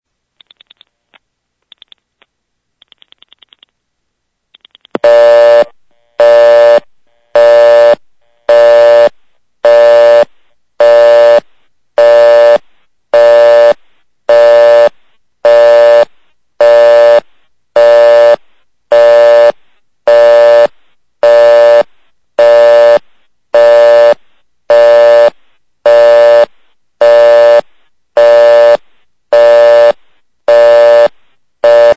These were made over the Collector’s network  (C-Net) using an Analog Telephone Adapter (ATA) via the Internet.